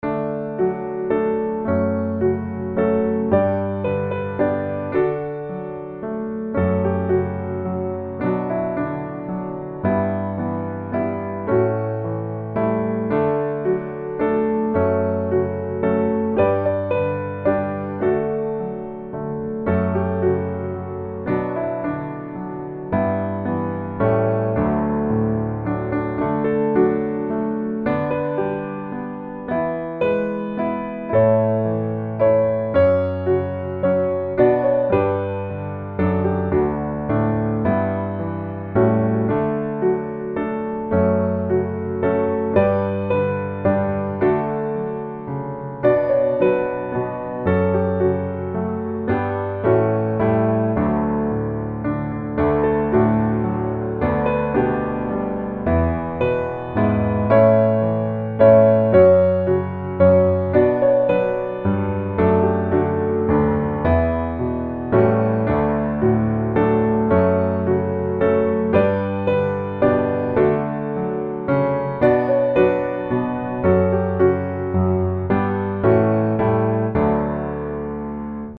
Calms my nerves
Gospel
D Major